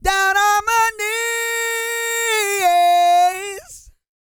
E-GOSPEL 245.wav